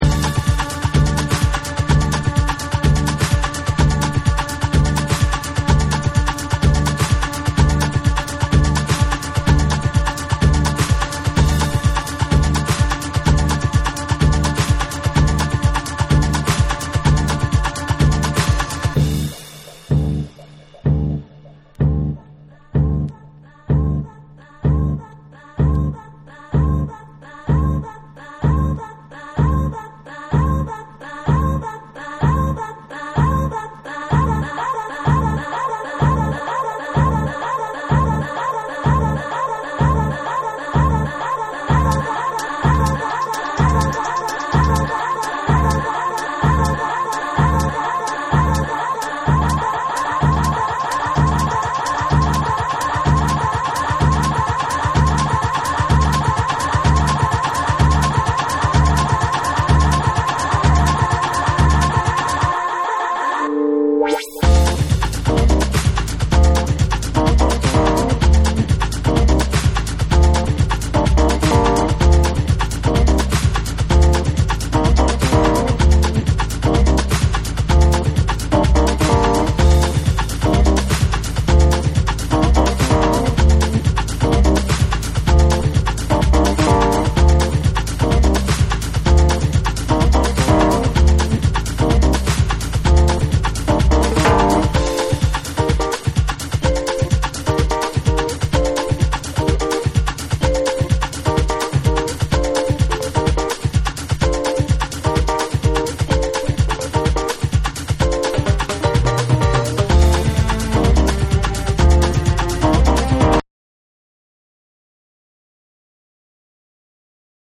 パーカッションを交えたラテンのリズムに、軽快なピアノやスキャットが絡むフロア・キラーなジャジー・ブレイク！
BREAKBEATS / ORGANIC GROOVE